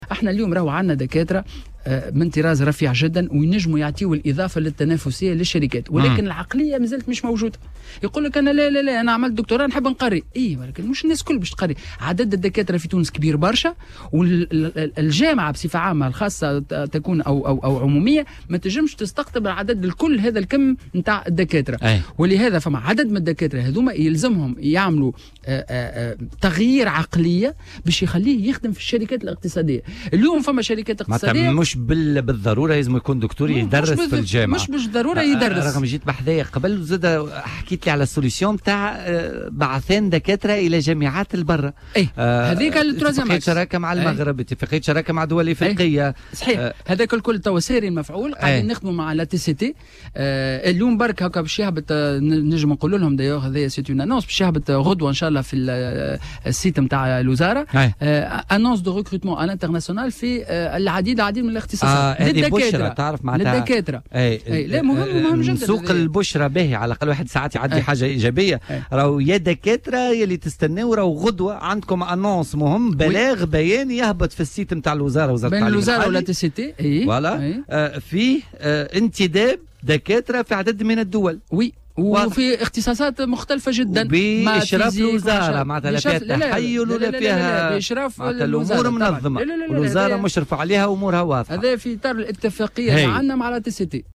وأضاف خلال استضافته اليوم الخميس 20 سبتمبر 2018 في برنامج "بوليتيكا"، أن الانتدابات ستكون في عدة دول وتشمل اختصاصات متعددة، وستكون تحت إشراف الوزارة في إطار قانوني.